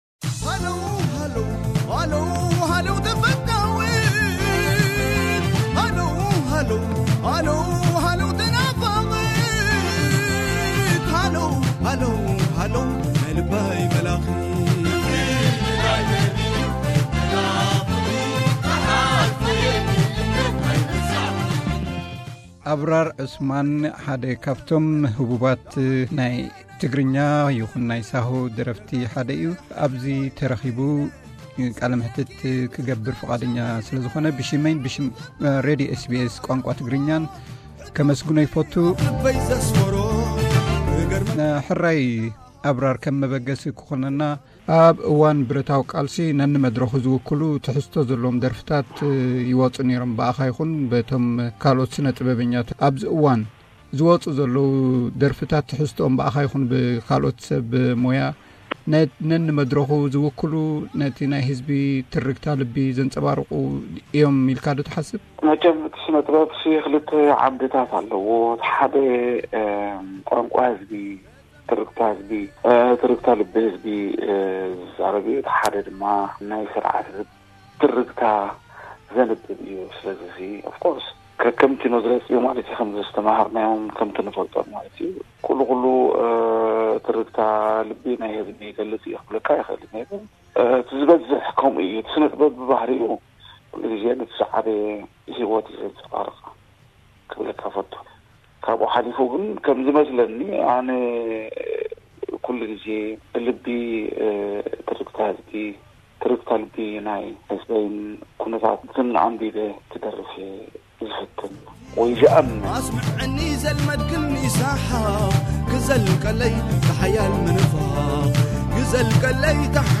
Abrar Osman is a legend Tigrinya and saho singer, he talks about his music carrier and personal life. This exclusive interview will take you to the personal experience of the great singer!